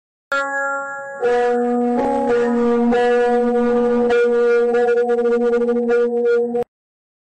Scary Sound Xdd Meme
scary-sound-xdd-meme.mp3